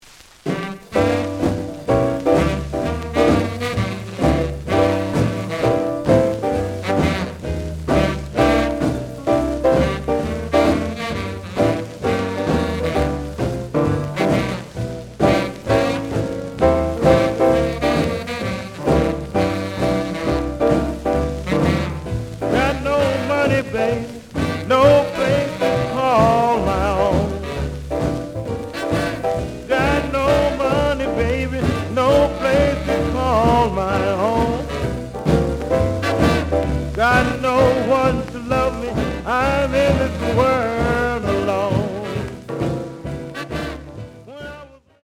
試聴は実際のレコードから録音しています。
●Genre: Rhythm And Blues / Rock 'n' Roll
●Record Grading: VG (両面のラベルにダメージ。盤に若干の歪み。プレイOK。)